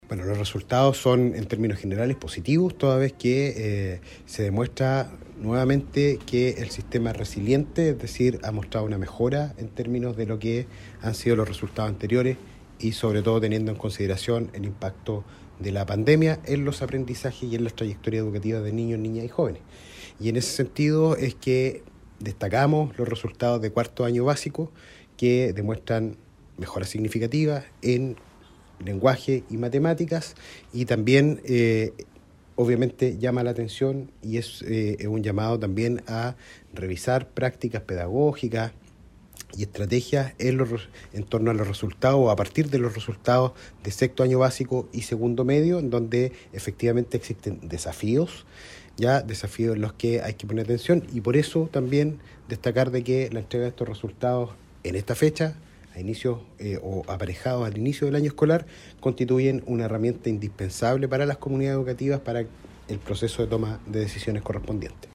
El seremi de Educación, Carlos Benedetti, entregó el balance de estos resultados para la región del Biobío.